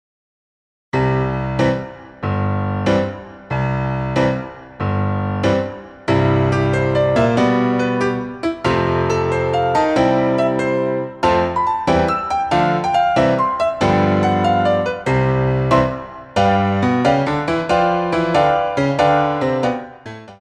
Grand Battement